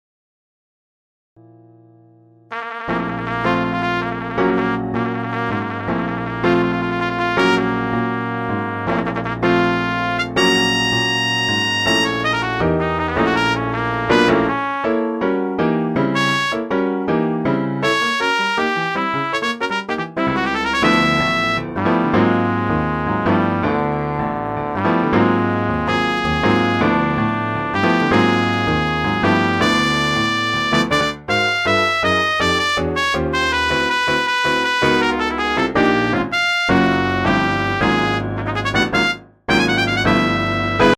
For Flugelhorn and Piano
Combination of beautiful melodies and fire.